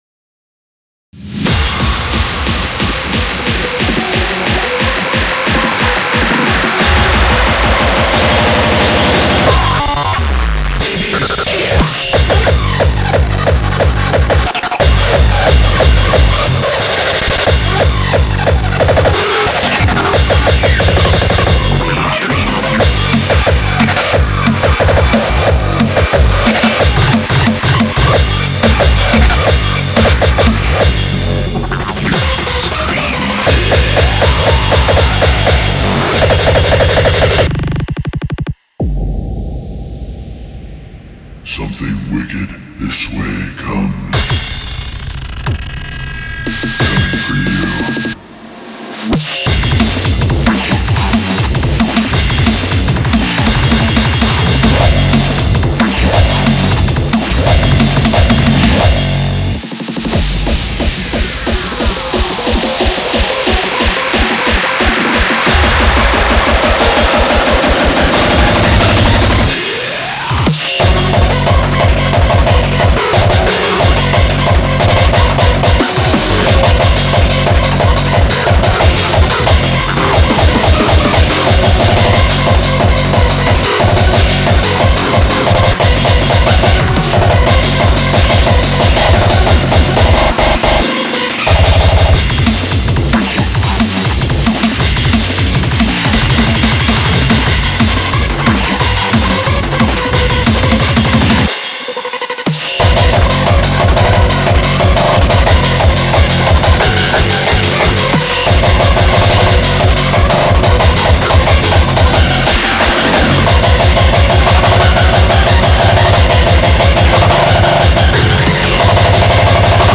BPM180
Audio QualityPerfect (High Quality)
Comments[MAINSTREAM HARDCORE]